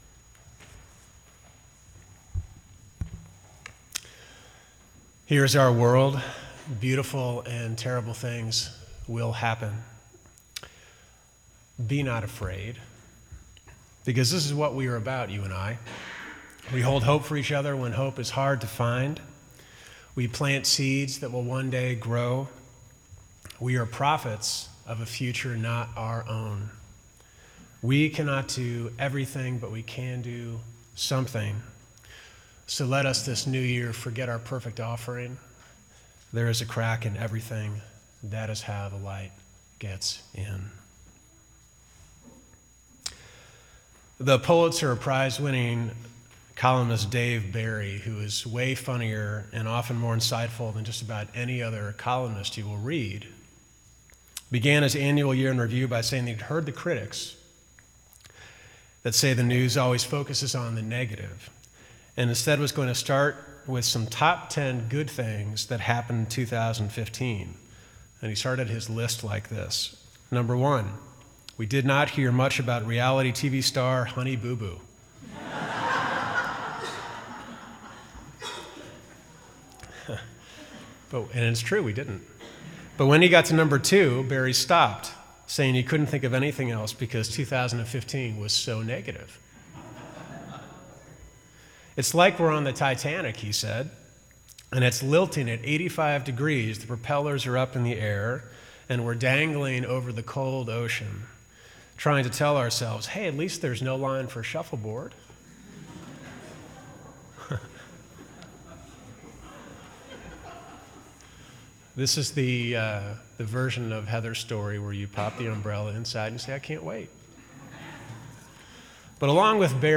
Sermon1_3_16.mp3